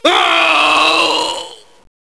mil_die2.wav